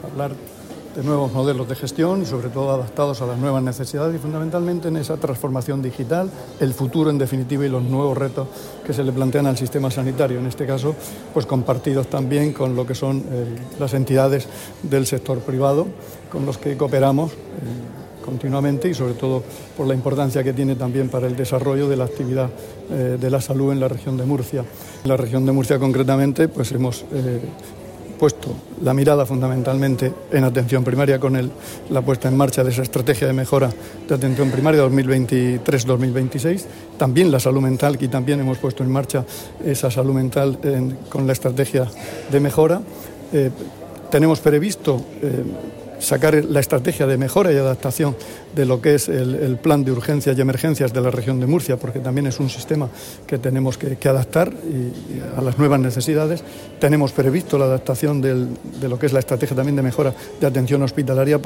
Declaraciones del consejero de Salud, Juan José Pedreño sobre las estrategias de gestión sanitaria puestas en marcha en la Región de Murcia.